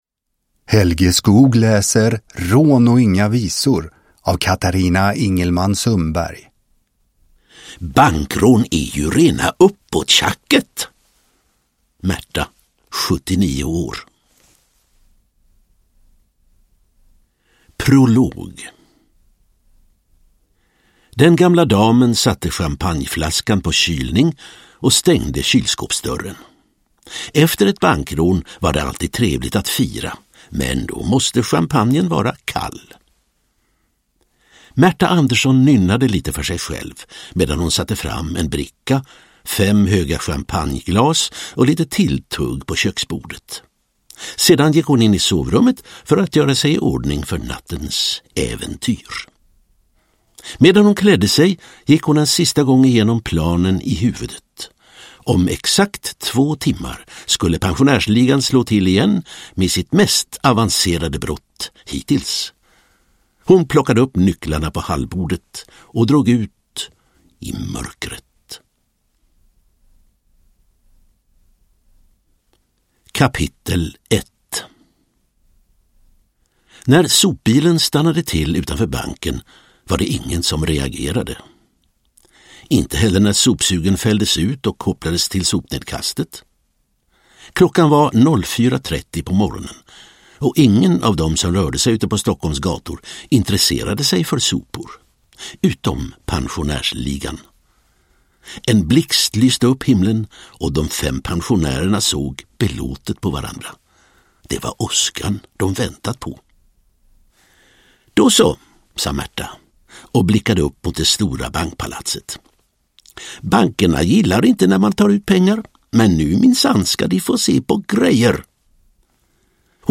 Uppläsare: Helge Skoog
Ljudbok